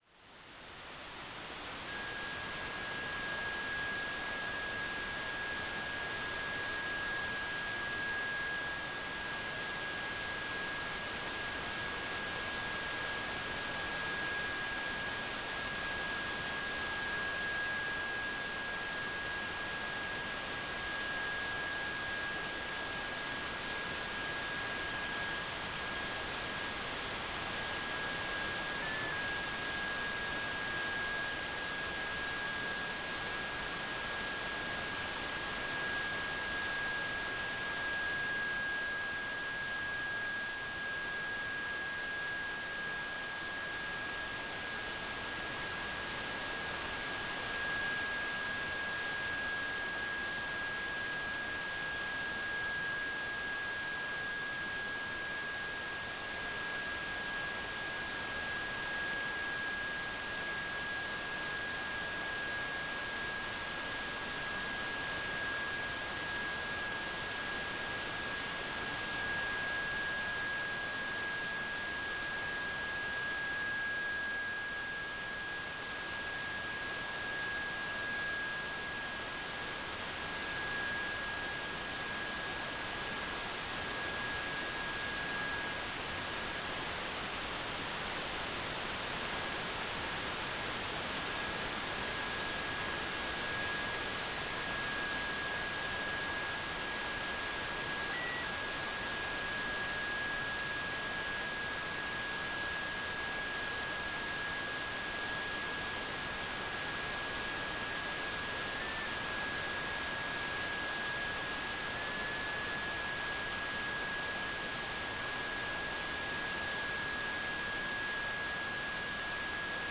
"transmitter_description": "CW",
"transmitter_mode": "CW",